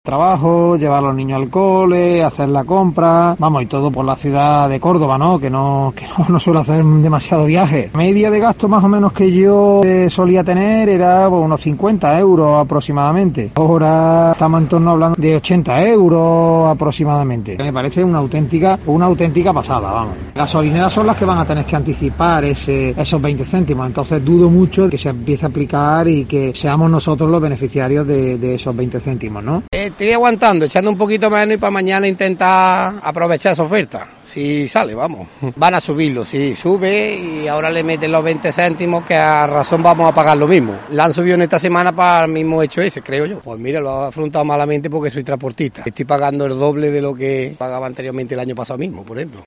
Escucha a transportistas y ciudadanos ante la posible bajada del precio del carburante